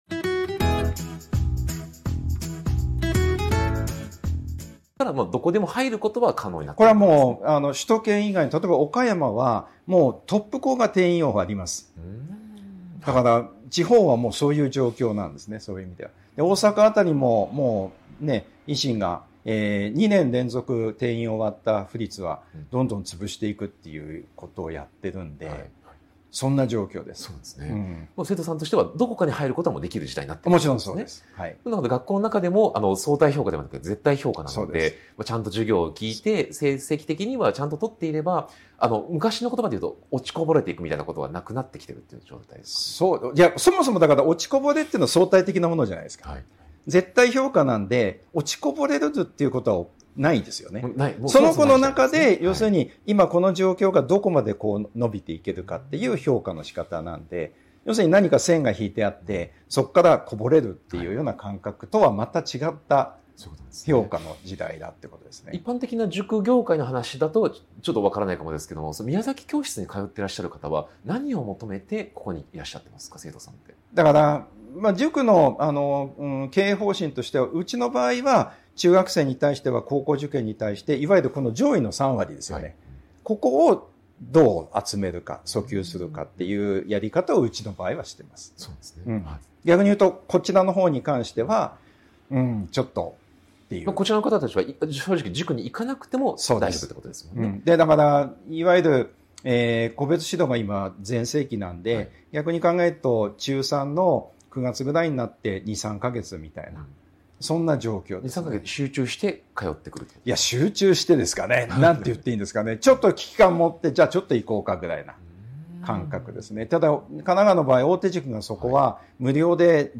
【特別インタビュー】塾業界38年の現場から見える未来の学習塾経営とは 個人塾が生き残るための「縦展開」と「寄り